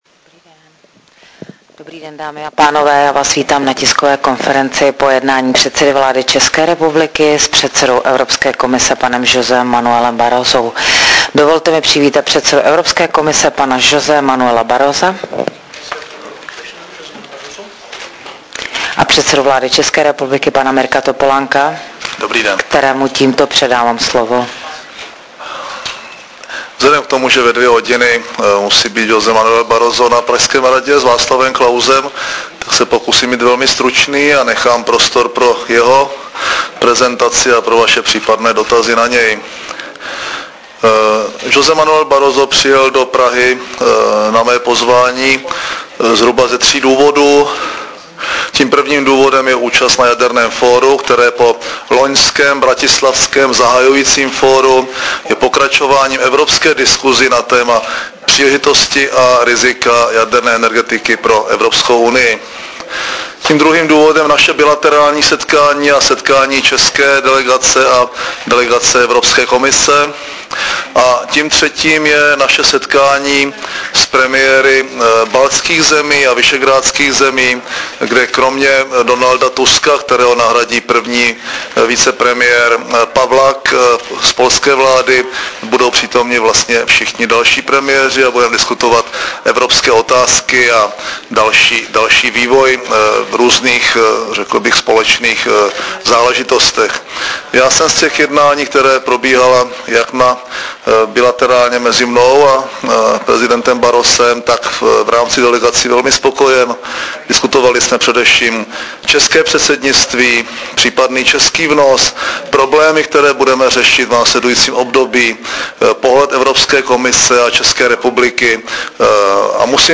Tisková konference po jednání premiéra Mirka Topolánka a předsedy Evropské komise José Manuela Barrosa 22.5.2008 v Hrzánském paláci